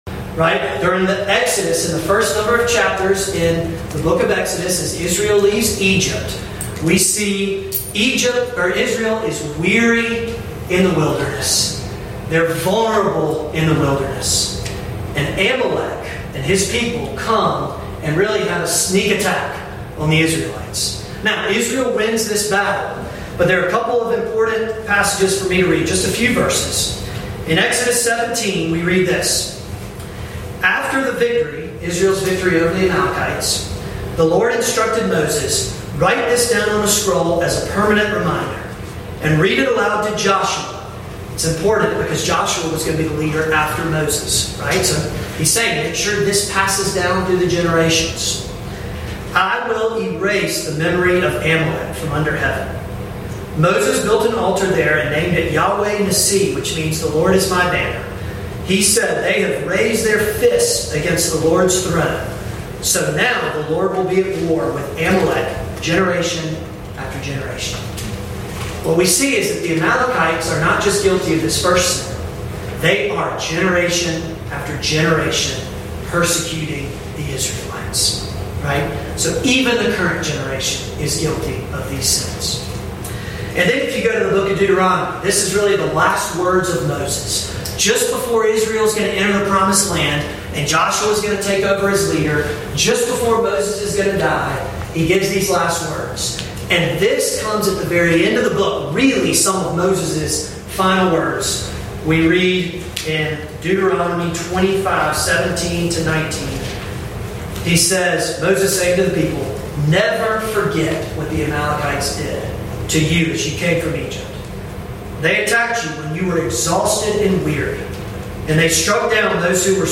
(Note: There was an issue with the recording of this sermon and the first 10 to 15 minutes were not usable)